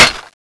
Index of /server/sound/weapons/tfa_cso/hk121_custom
clipout_2.wav